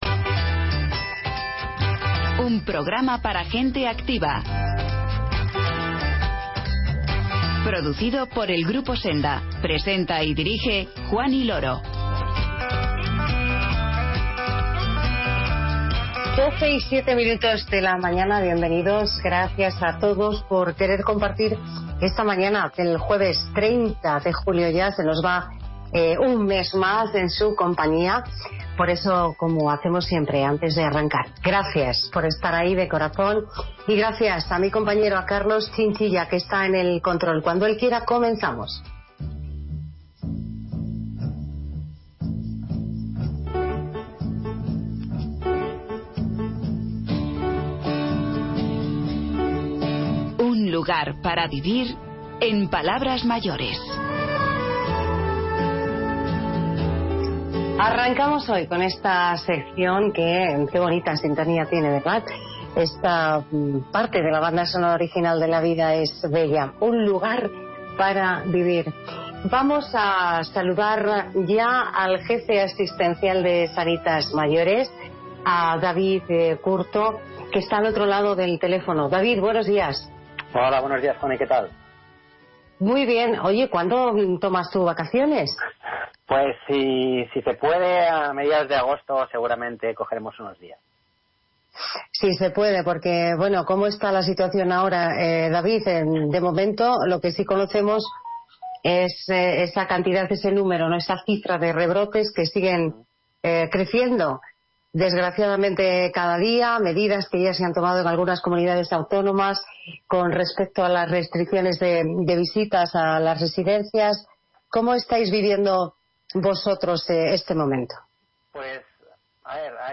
Recordamos algunas de las canciones del verano de nuestro pasado más reciente.